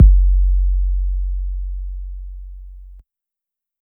Kick (17).wav